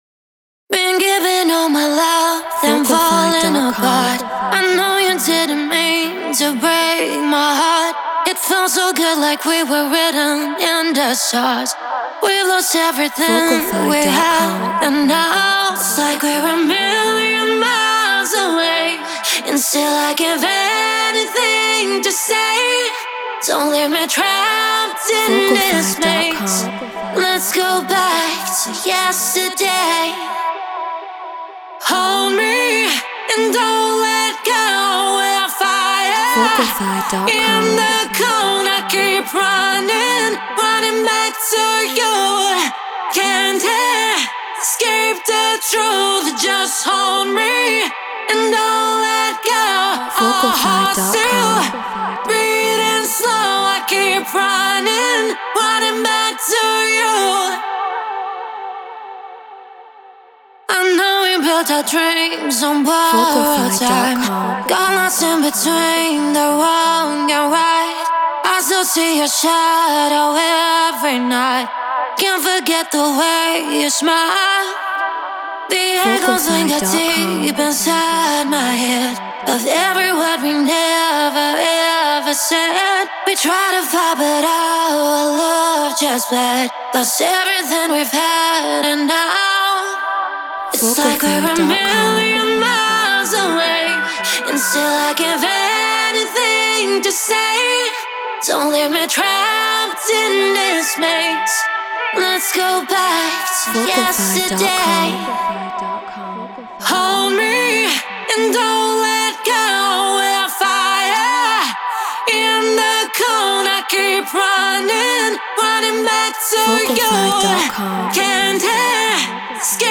House 128 BPM Emin
Brauner VMX Apogee Elements 88 Ableton Live Treated Room